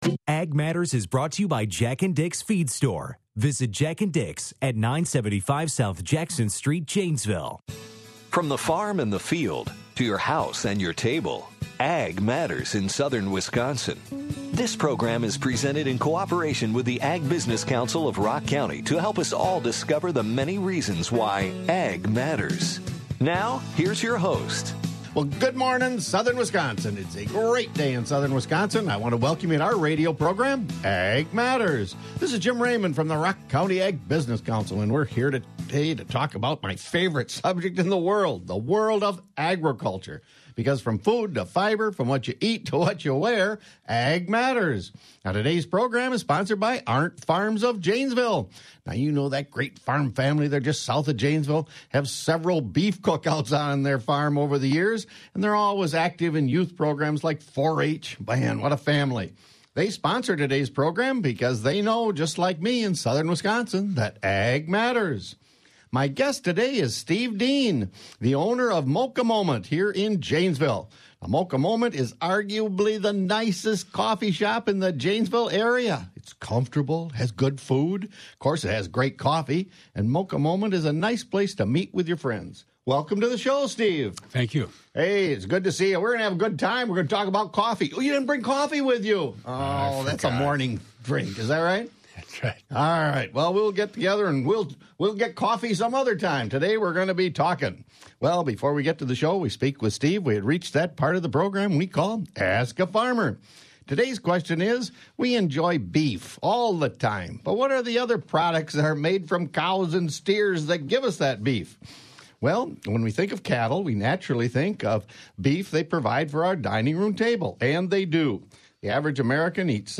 Interviewed on Ag Matters